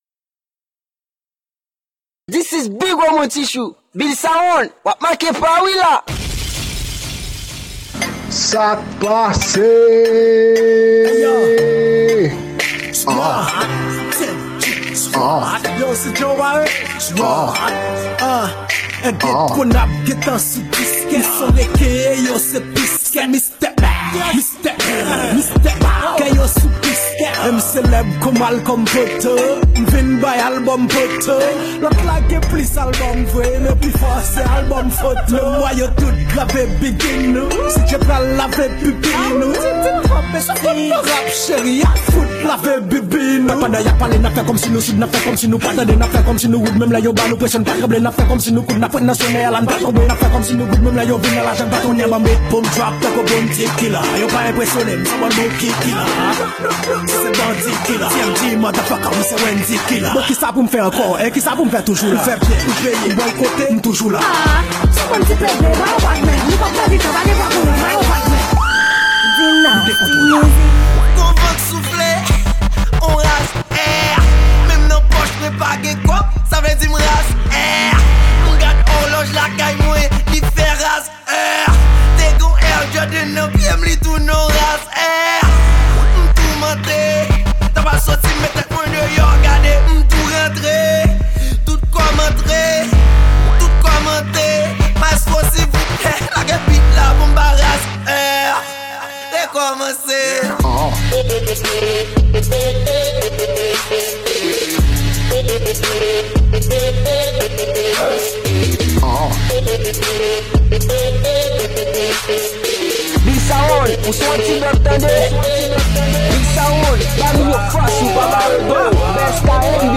Genre: WORLD MUSIC.